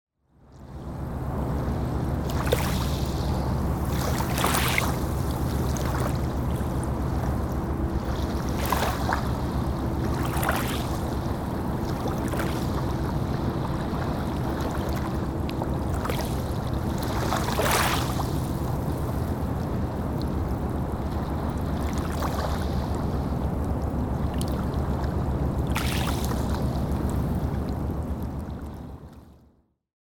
色々な都会の喧騒_3（都会の喧騒と波の音）